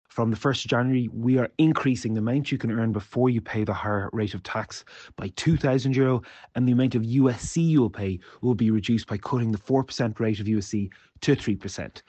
Junior Finance Minister Neale Richmond says there's also USC changes coming: